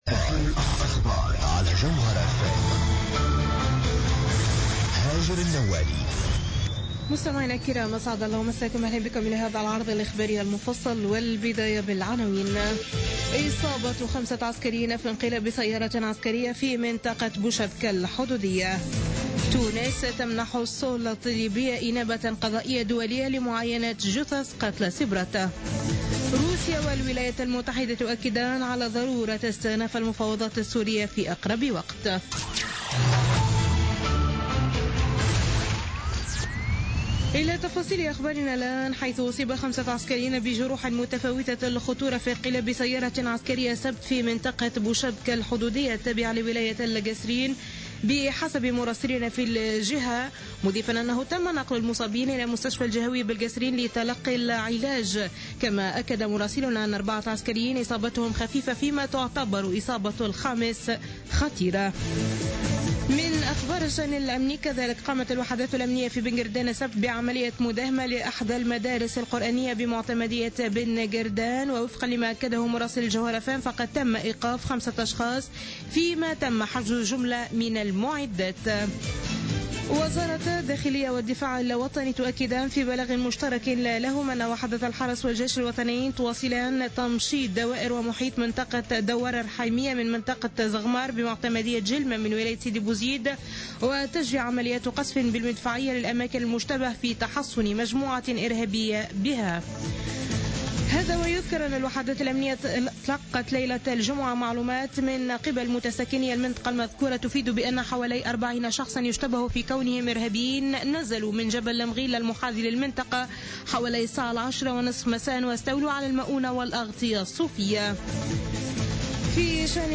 نشرة أخبار منتصف الليل ليوم الأحد 6 مارس 2016